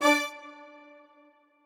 strings8_2.ogg